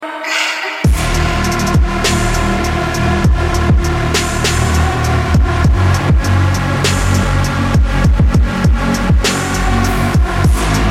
Помогите накрутить такой lead
Всем привет,подскажите как накрутить такой lead и bass и в каком плагине.